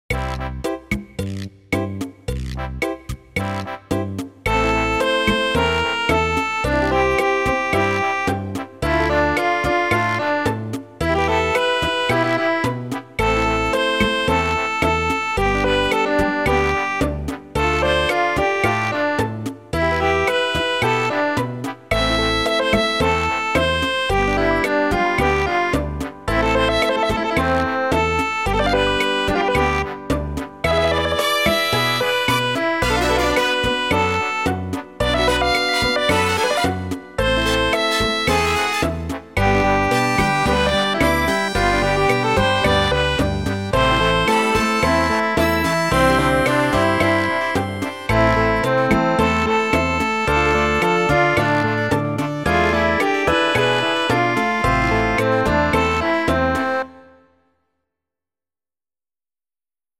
【拍子】4/4 【種類】 【演奏】火打ち石の音が入ってます。